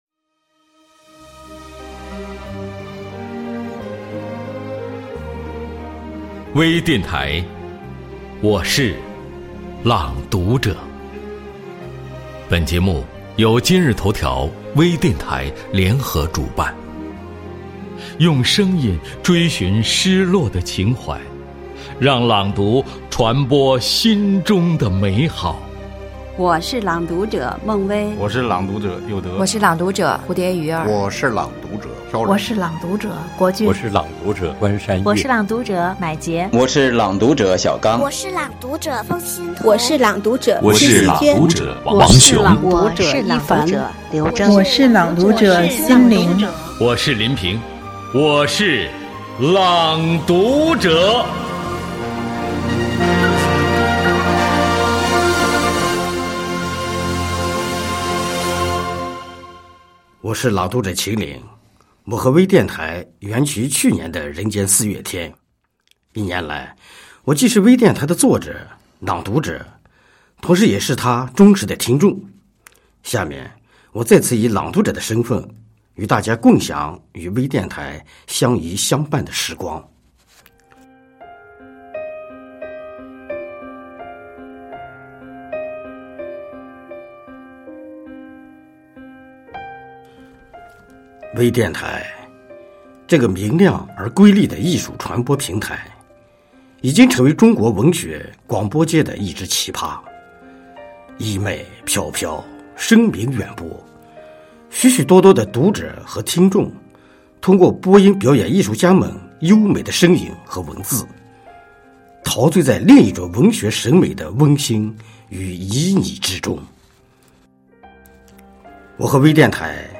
多彩美文  专业诵读
作者/朗诵者